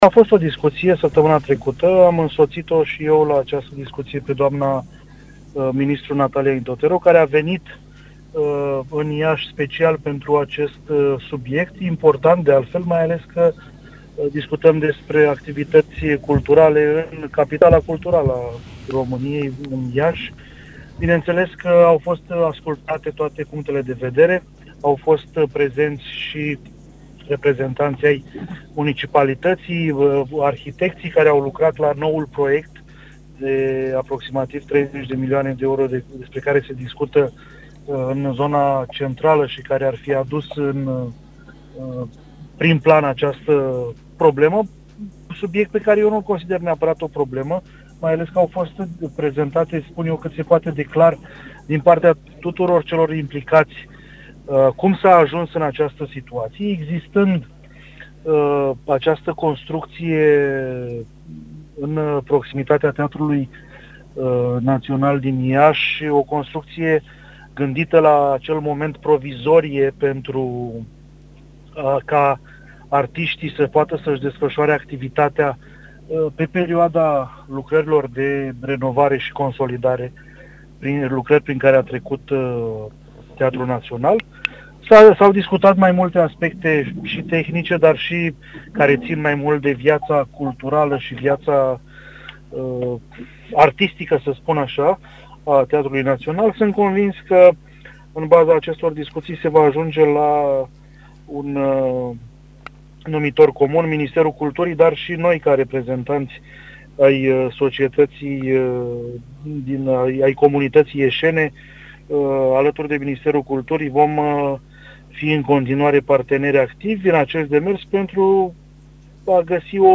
interviu-Bogdan-Cojocaru-unu.mp3